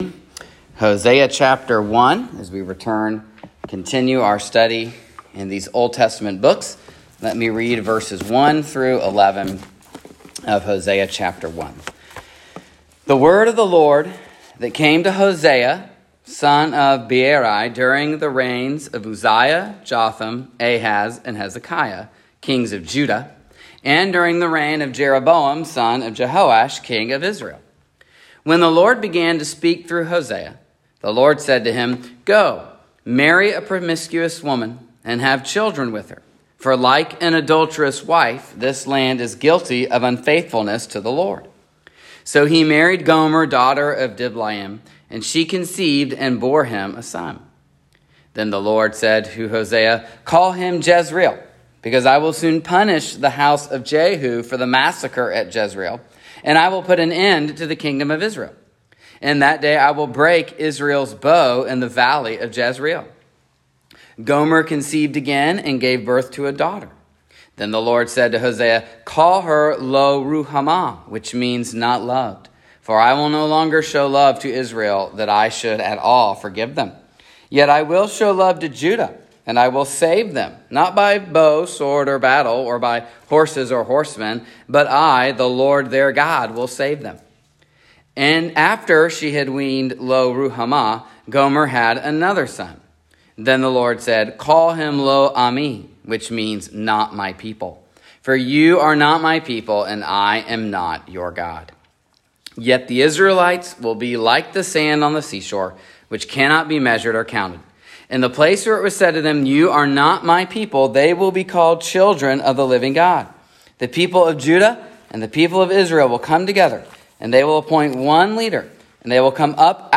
Hosea 1:1-11 Service Type: Sunday Evening Hosea best known for his marriage to an adulterous woman.